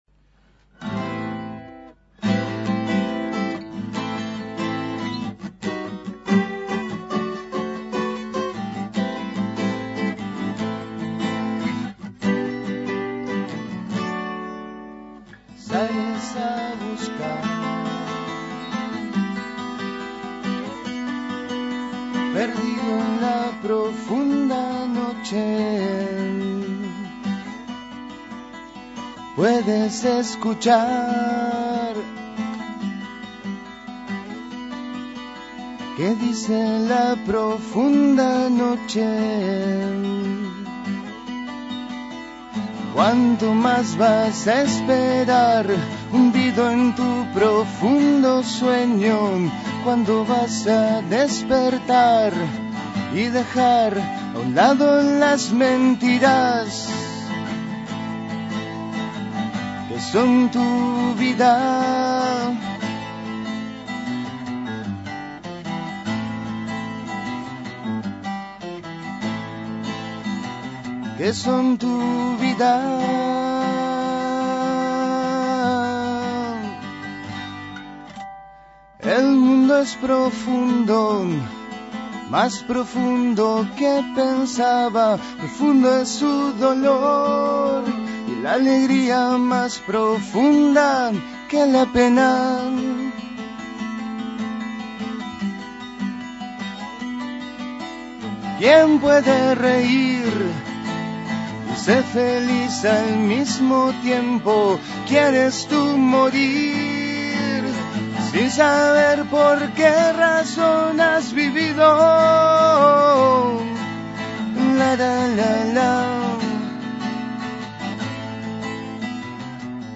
Ambos fueron entrevistados en la Segunda Mañana de En Perspectiva.